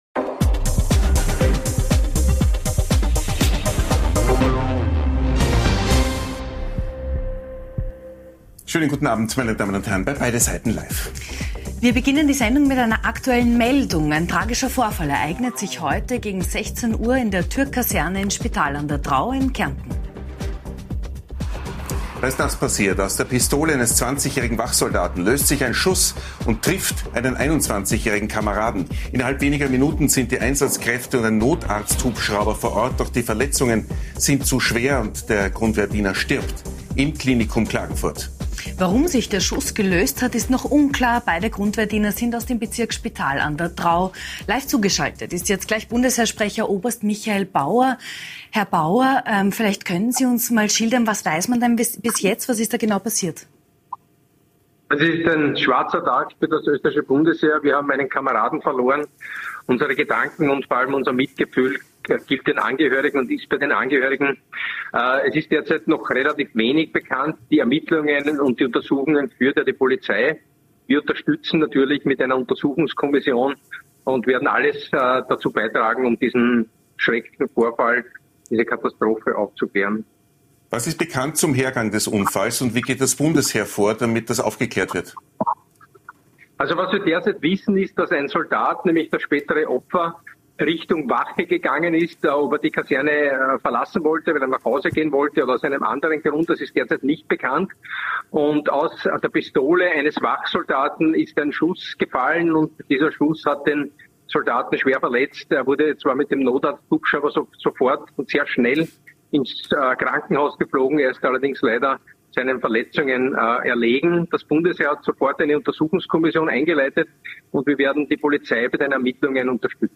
Die Klage sei ohne Zustimmung der Grünen nicht möglich, Nachgefragt haben wir heute bei gleich zwei Gästen - im großen Beide Seiten Live Duell mit der ehemaligen FPÖ-Politikerin Ursula Stenzel und dem aktiven SPÖ-Mitglied und Physiker Werner Gruber.